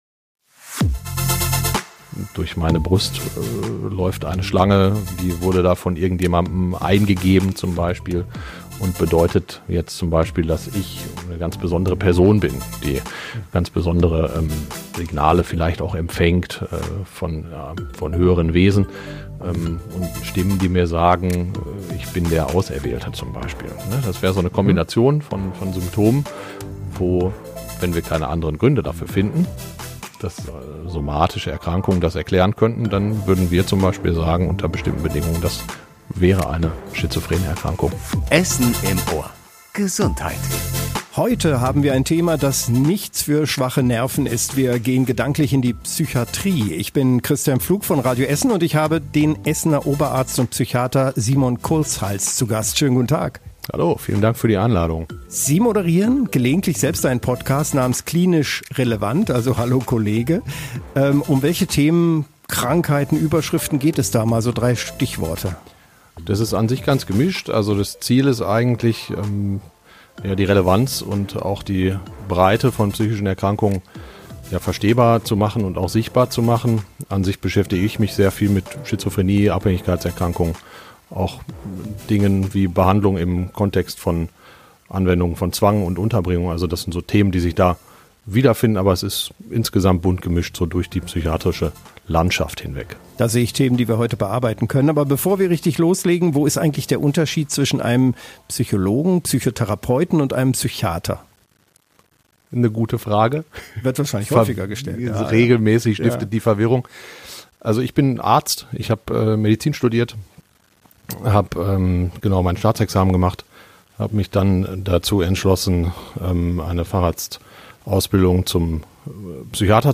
Erfahrene Mediziner:innen aus Essen kommen zu Wort.